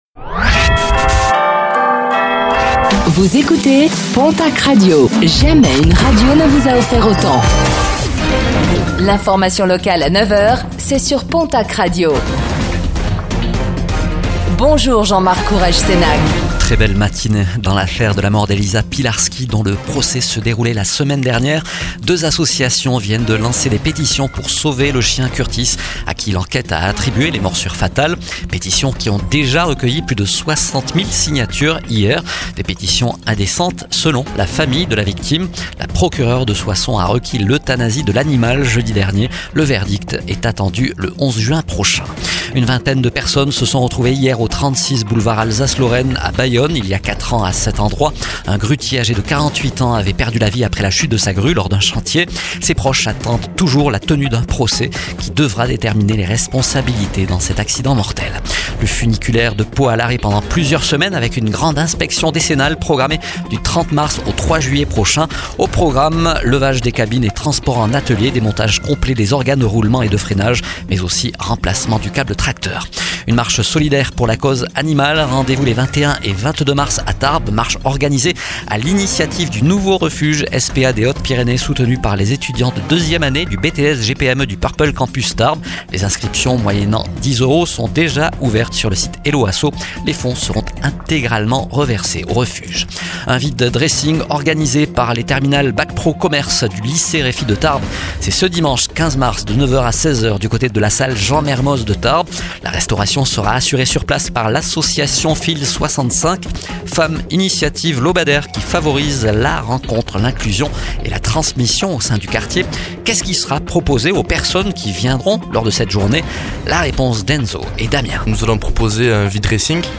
Infos | Mardi 10 mars 2026